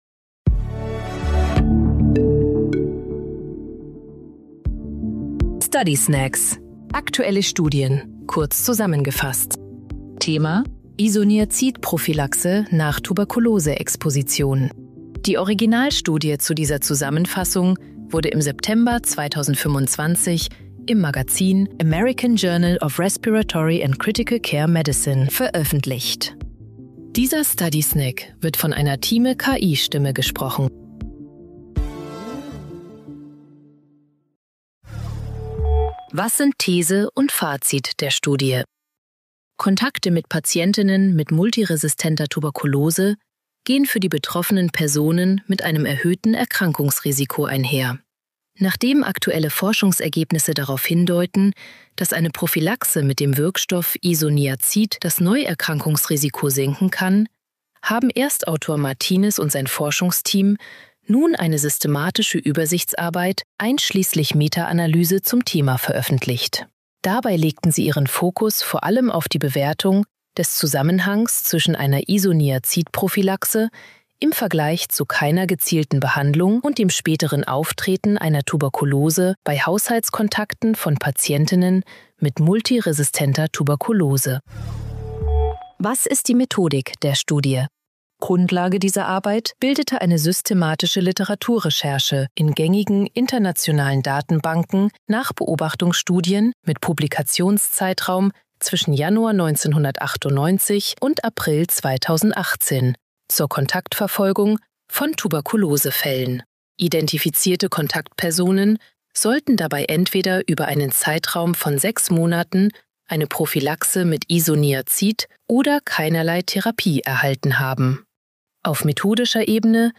künstlicher Intelligenz (KI) oder maschineller